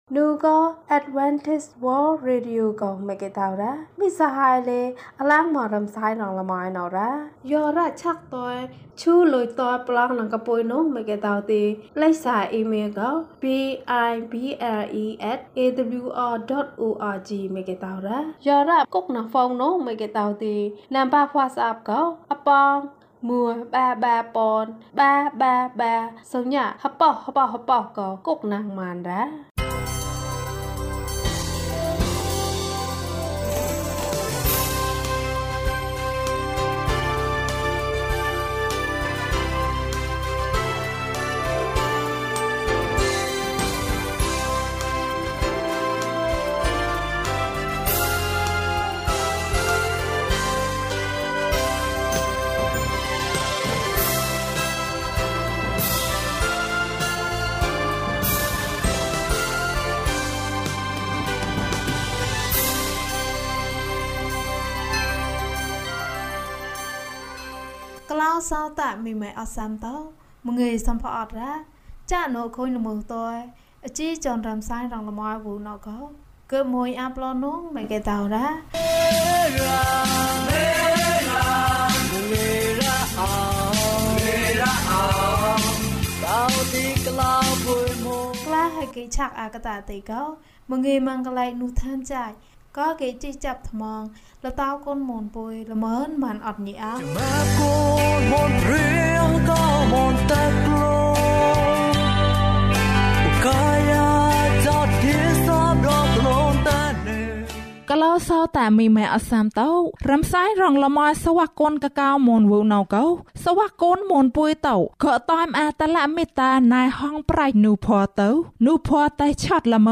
ဘုရားသခင်က ကောင်းတယ်။၀၁ ကျန်းမာခြင်းအကြောင်းအရာ။ ဓမ္မသီချင်း။ တရားဒေသနာ။